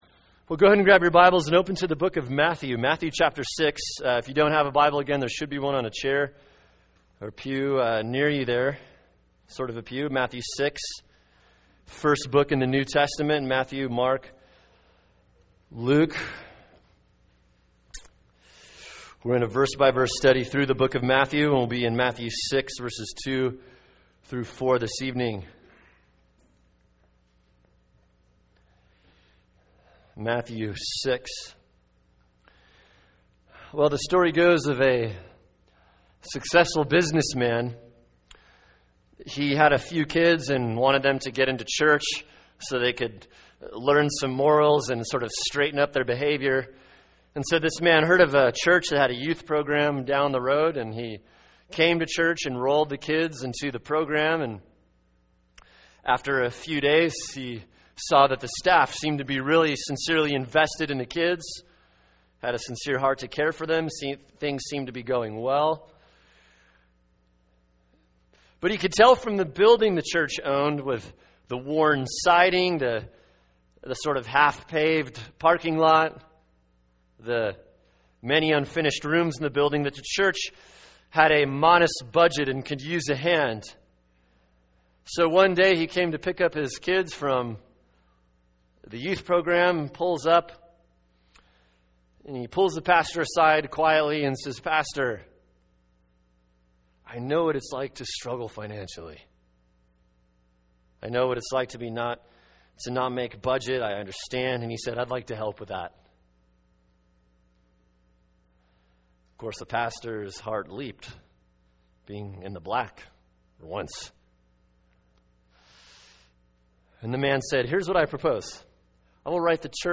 [sermon] Matthew 6:2-4 “Religiatrics and Giving” | Cornerstone Church - Jackson Hole